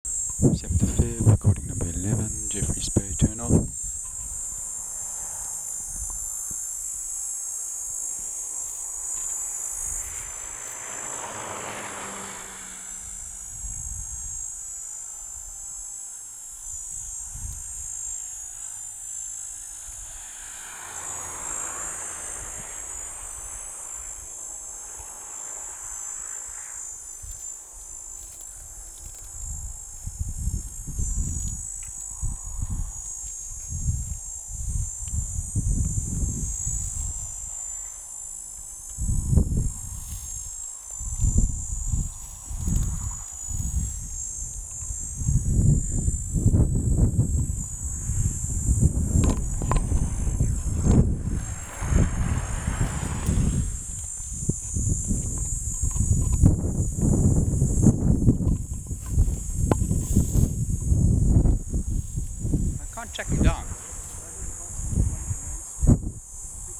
Platypleura "sp. 13"